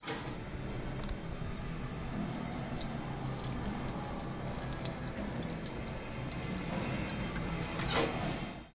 描述：喷泉的声音激活和水流动。 记录在建造喷泉的tallers。
Tag: UPF-CS14 TALLERS 校园-UPF 喷泉 场记录